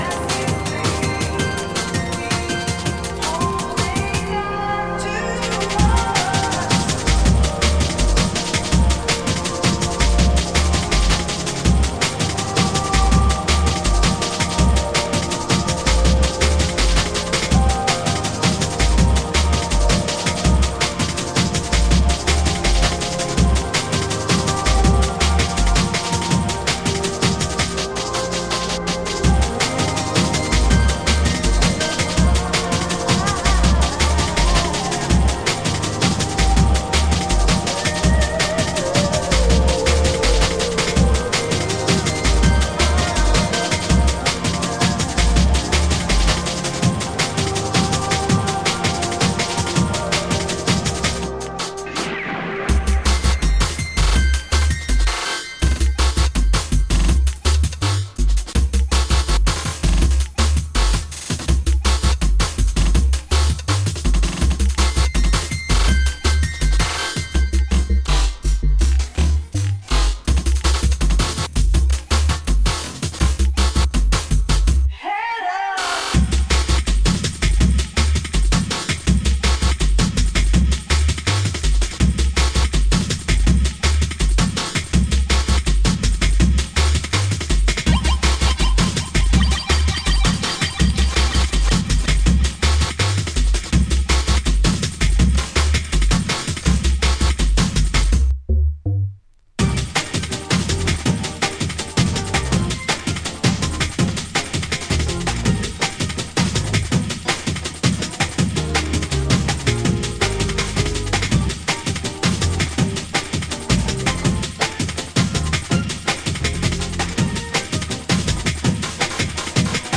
pisca o olho a todo o Jungle mais soulful dos 90s